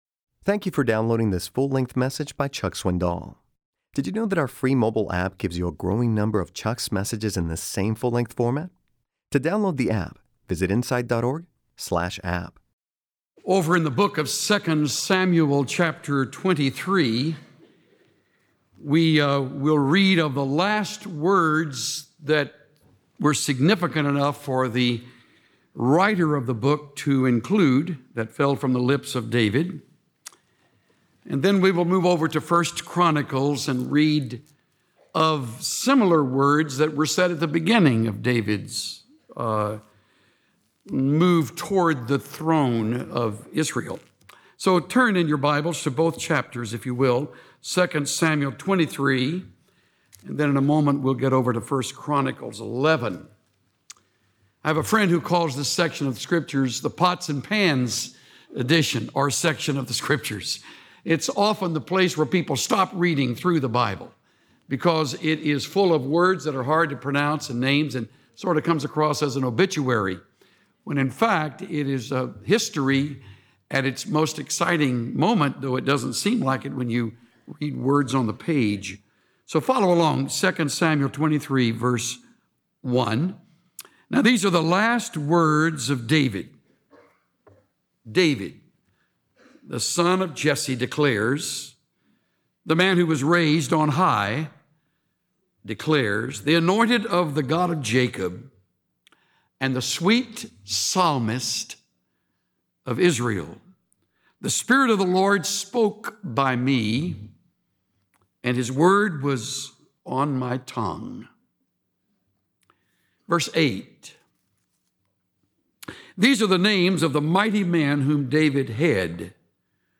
Pastor Chuck Swindoll teaches us not to fear the dark storm but to trust God’s grace.